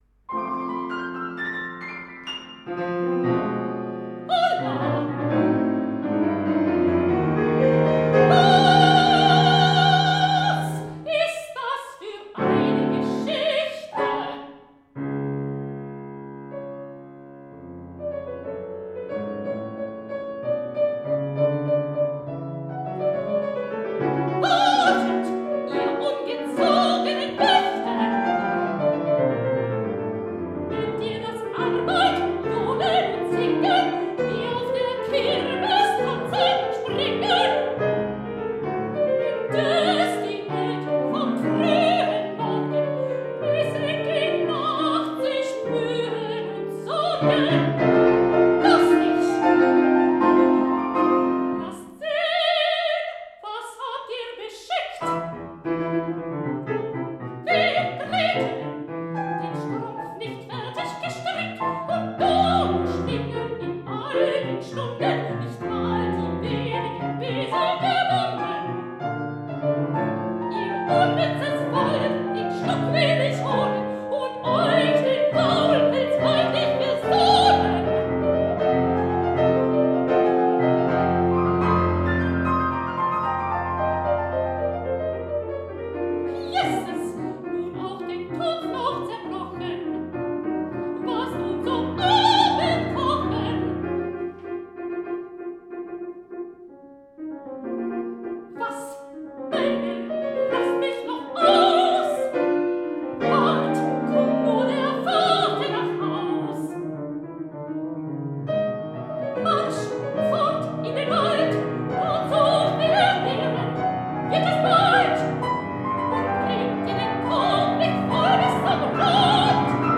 Mezzosopran
Mitschnitt vom August 2018